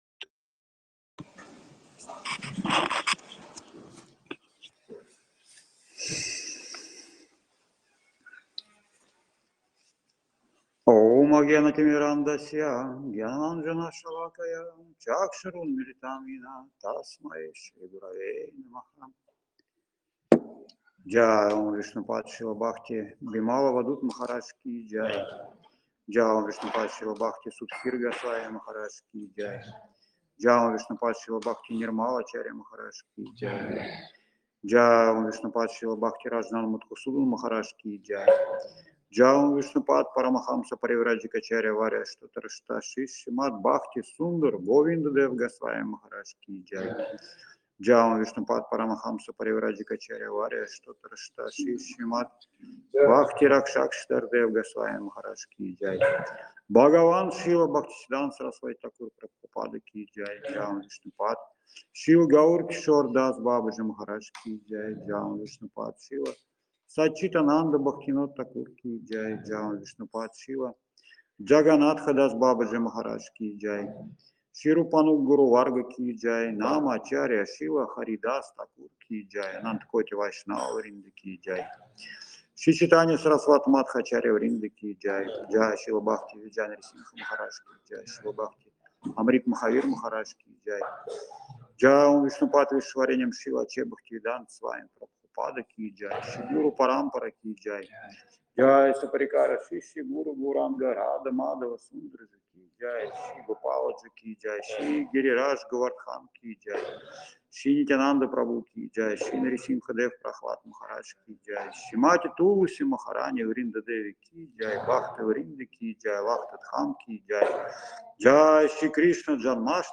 Опубликовано: 31 августа 2024 «Шри Кришна-джанмаштами». Общение с преданными перед инициацией.
Лахта, Санкт-Петербург
Лекции полностью